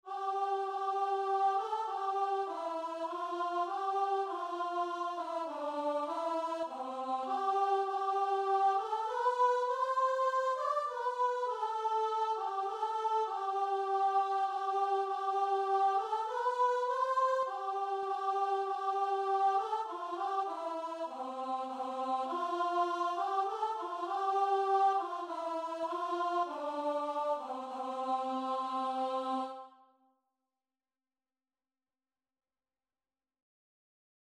Christian Christian Guitar and Vocal Sheet Music We Gather Together
Free Sheet music for Guitar and Vocal
3/4 (View more 3/4 Music)
C major (Sounding Pitch) (View more C major Music for Guitar and Vocal )
Guitar and Vocal  (View more Intermediate Guitar and Vocal Music)
Traditional (View more Traditional Guitar and Vocal Music)